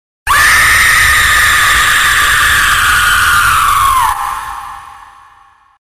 Sonic.exe scream Meme Effect sound effects free download
Sonic.exe scream - Meme Effect Mp3 Sound Effect